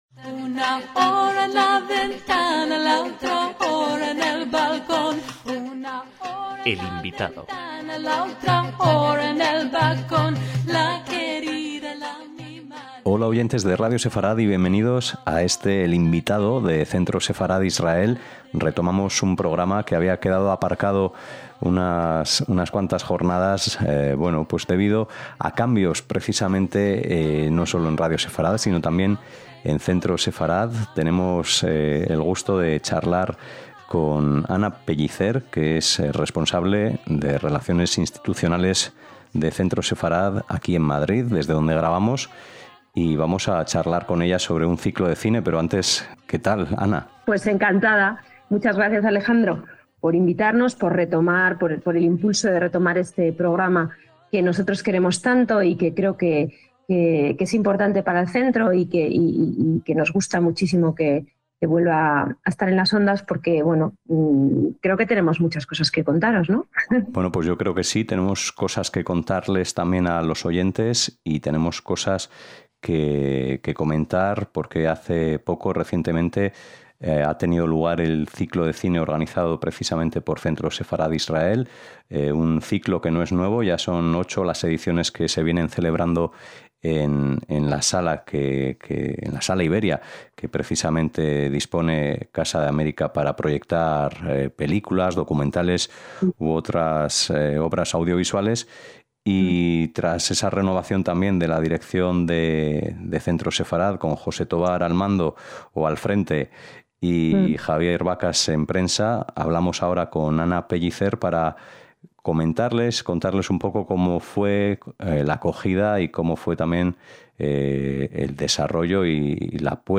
EL INVITADO DEL CENTRO SEFARAD-ISRAEL - Con motivo de la conmemoración del mes de la Memoria por las víctimas del Holocausto, Centro Sefarad-Israel (calle Mayor, 69 - Madrid), celebró su Ciclo de cine · Mes de la Memoria del Holocausto.